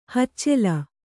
♪ haccela